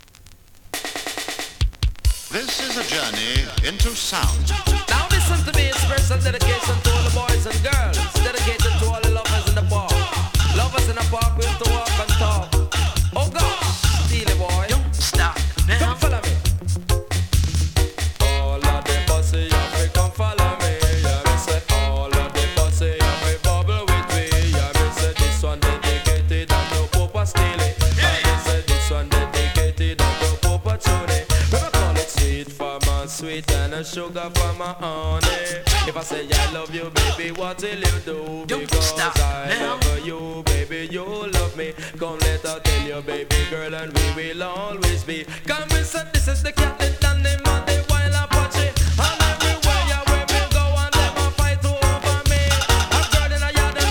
スリキズ、ノイズ比較的少なめで